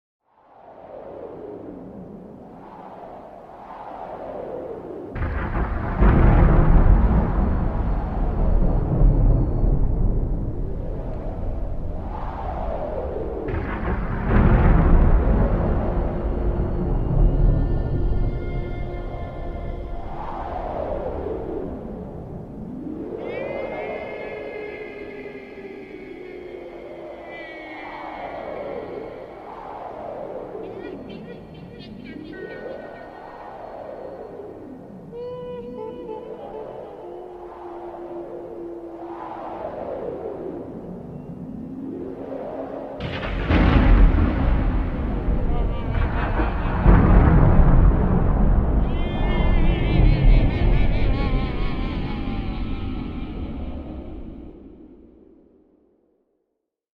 Evil Halloween Laugh Scary Warlock sound effect free sound royalty free Funny